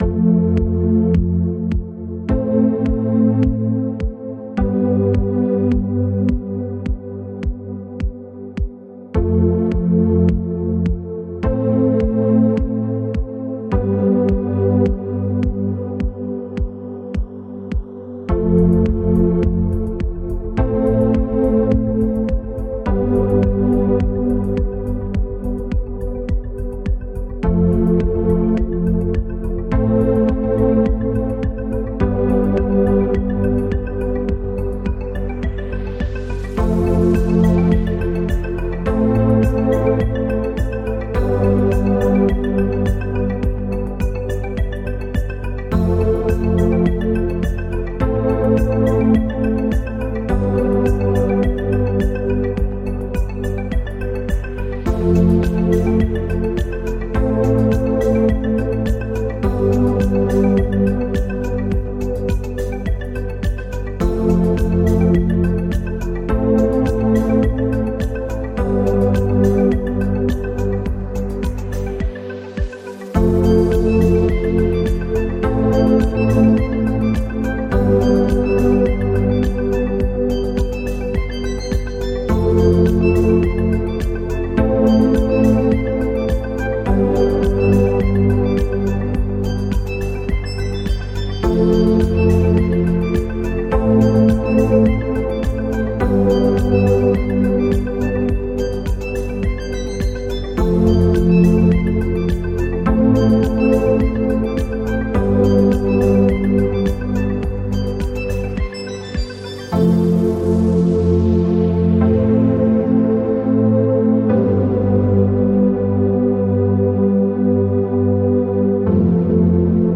Фоновая инструментальная музыка для видеомонтажа о музее